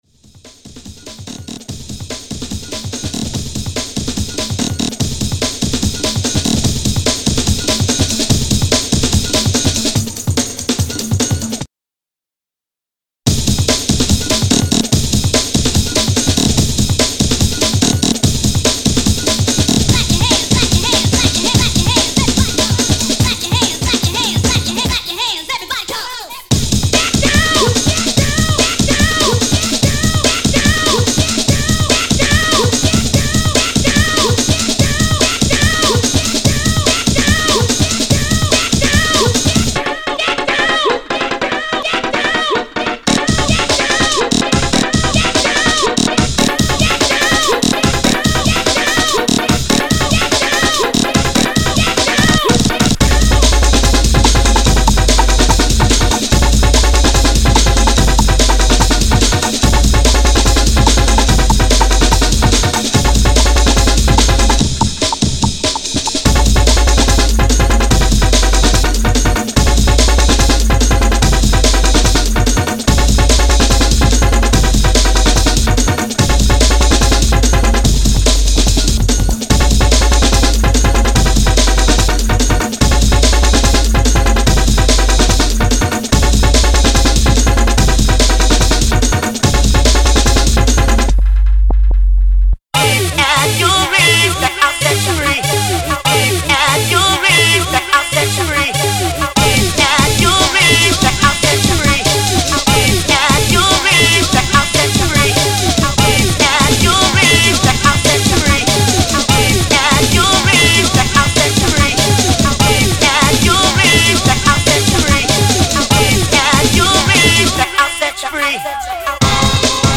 90s Jungle